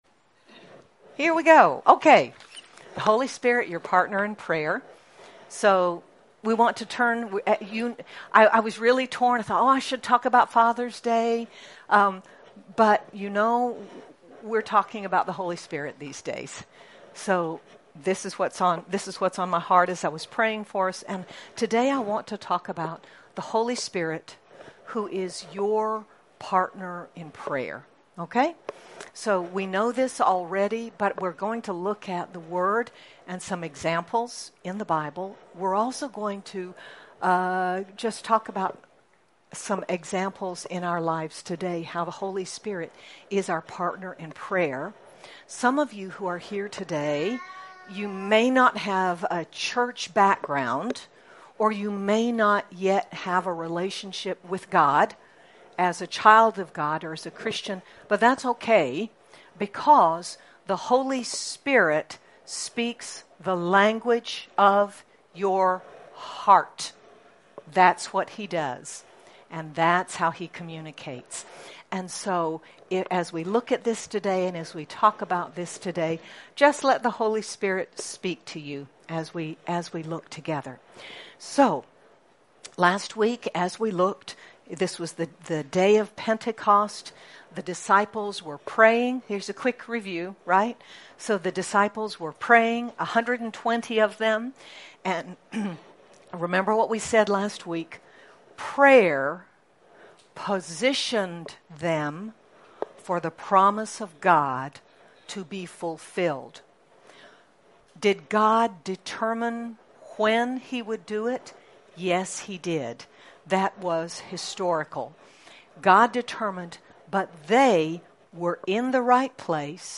The Holy Spirit, our Helper, is our partner in prayer. Sermon by